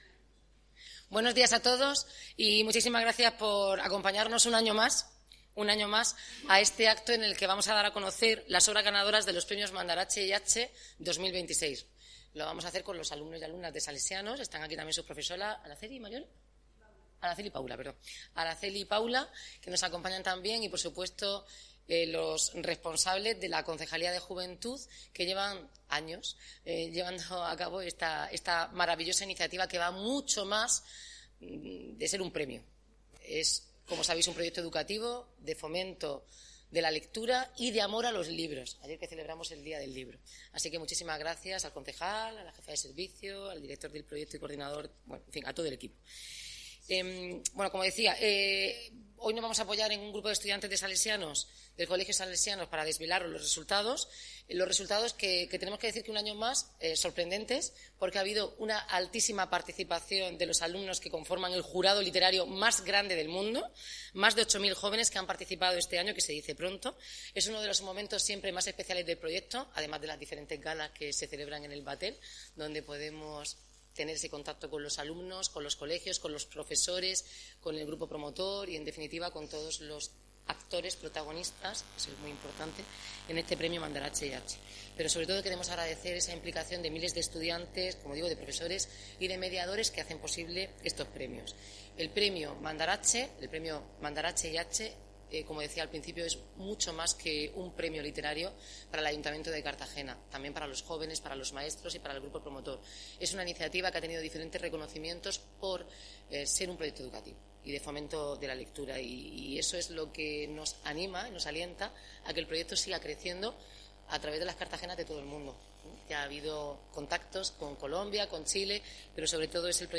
Este viernes 24 de abril se ha dado a conocer el fallo del jurado de los Premios Mandarache y Hache 2026, en un acto protagonizado por la alcaldesa de Cartagena, Noelia Arroyo, junto a un grupo de estudiantes del Colegio Salesianos Cartagena.